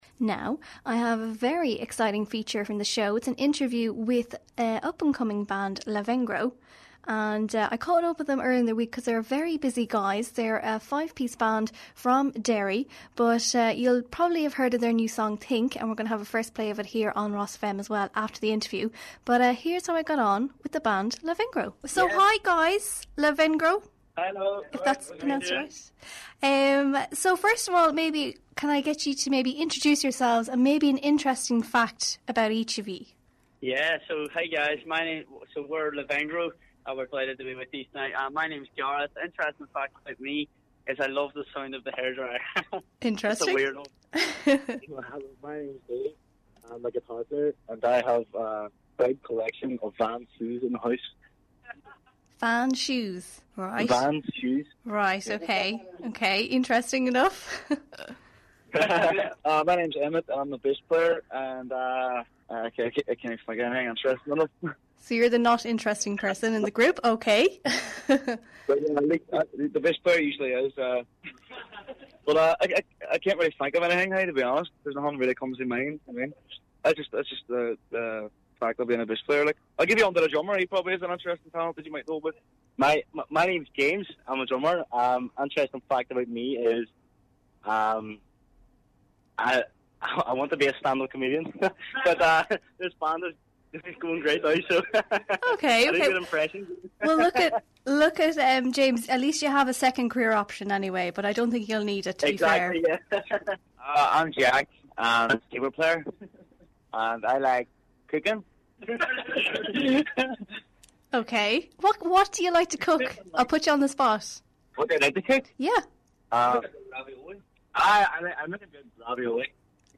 Derry Pop Band Lavengro Interview - RosFM 94.6